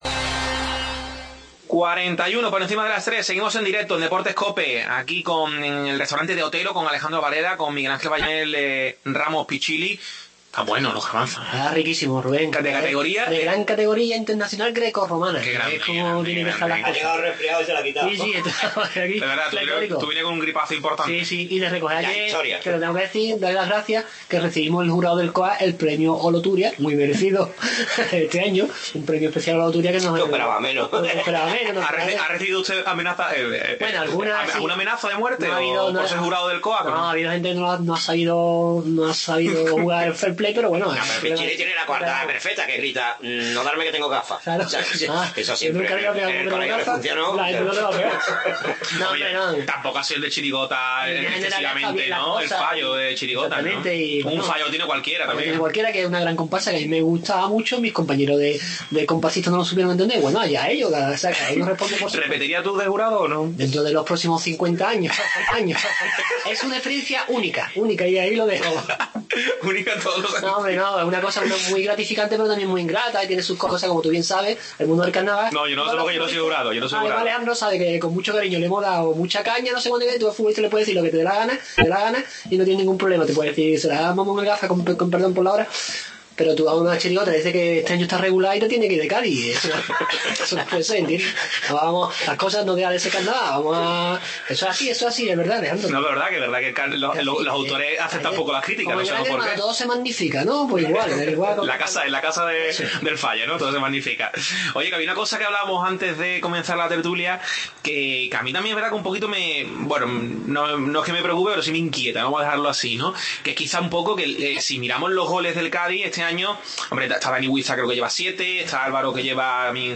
AUDIO: Continuamos analizando la actualidad del Cádiz desde el Restaurante De Otero